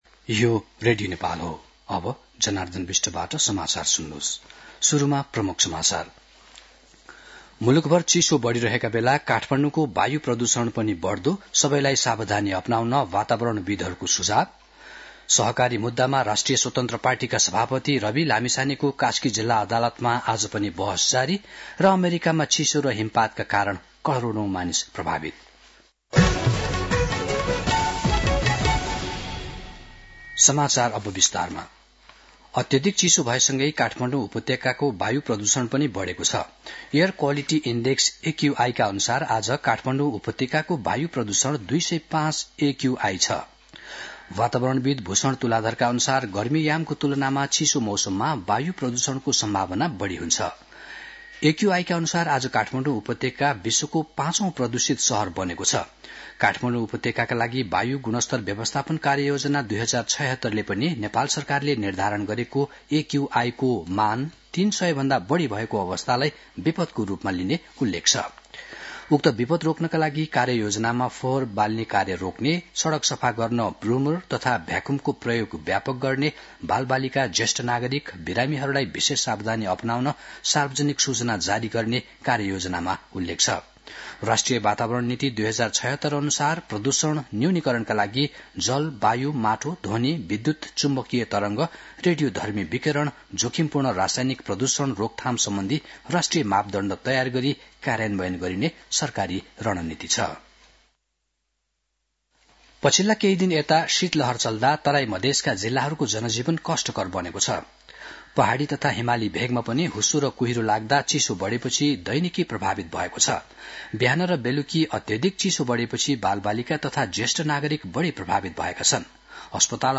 दिउँसो ३ बजेको नेपाली समाचार : २३ पुष , २०८१
3-pm-news-.mp3